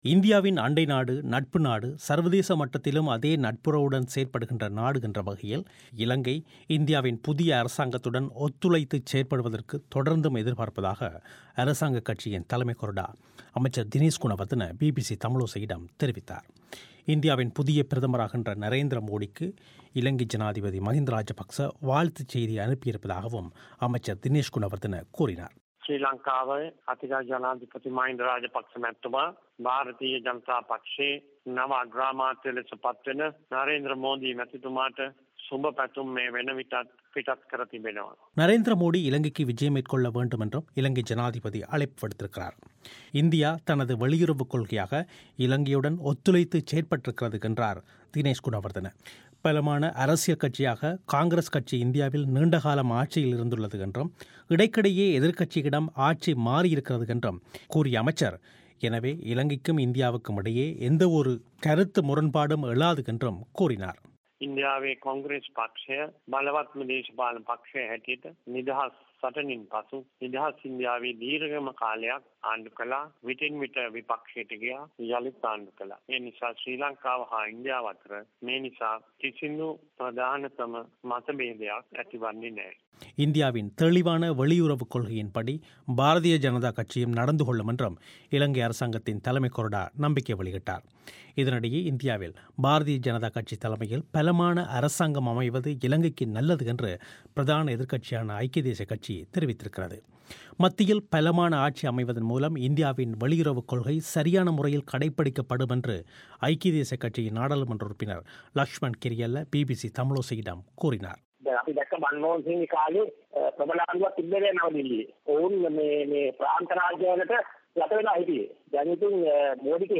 இந்தியாவின் அண்டை நாடு, நட்பு நாடு, சர்வதேச மட்டத்திலும் அதே நட்புறவுடன் செயற்படுகின்ற நாடு என்ற வகையில் இலங்கை, இந்தியாவின் புதிய அரசாங்கத்துடன் ஒத்துழைத்துச் செயற்படுவதற்கு தொடர்ந்தும் எதிர்பார்ப்பதாக அரசாங்கக் கட்சியின் தலைமை கொறடா அமைச்சர் தினேஷ் குணவர்தன பிபிசி தமிழோசையிடம் தெரிவித்தார்.